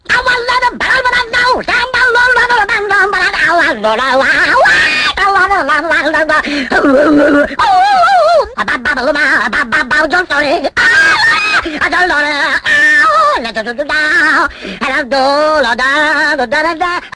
loud-song.mp3